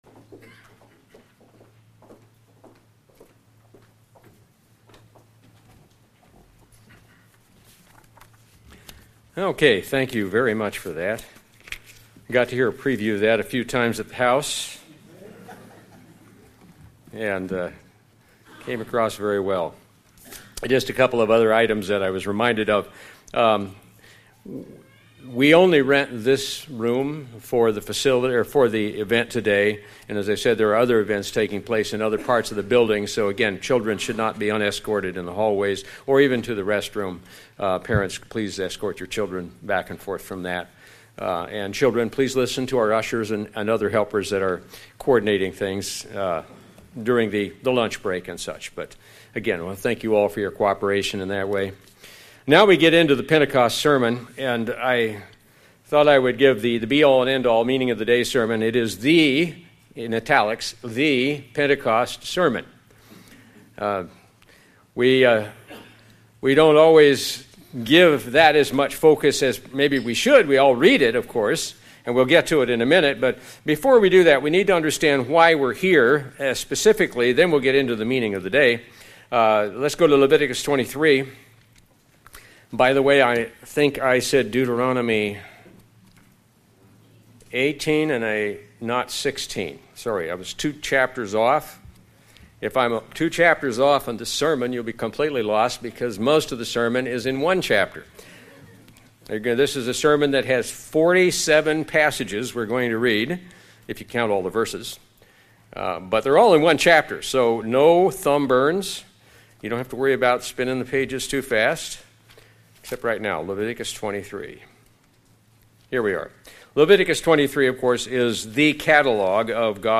The be-all, end-all, meaning-of-the-day sermon. It is the Pentecost sermon.